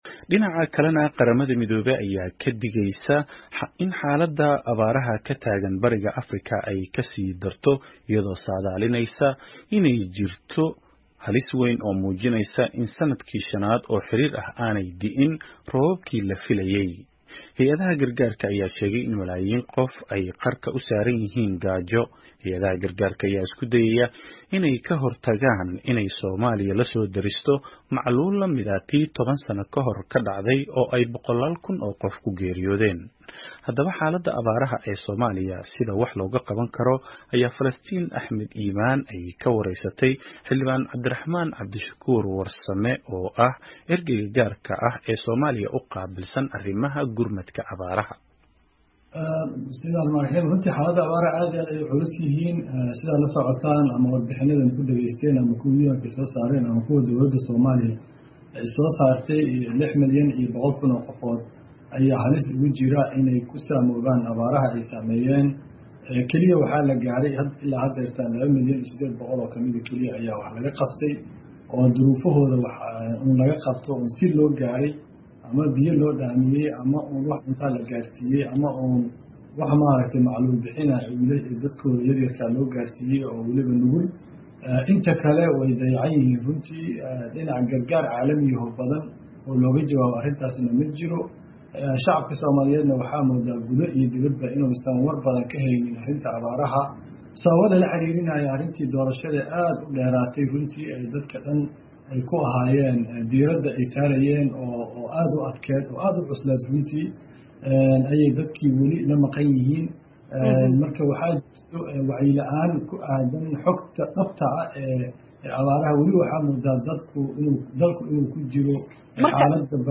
Wareysi: Cabdiraxmaan Cabdishakuur oo qeyla-dhaan horleh kasoo saaray abaaraha